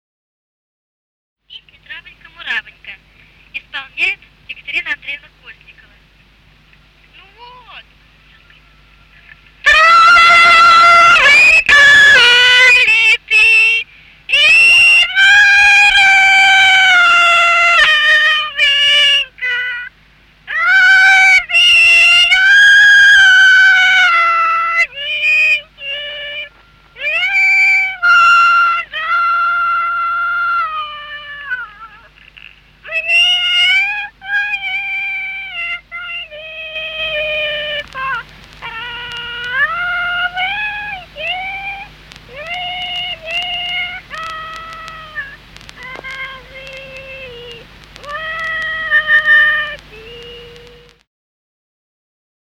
Русские народные песни Владимирской области [[Описание файла::26. Травонька-муравонька (хороводная) с. Сельцо Суздальского района Владимирской области.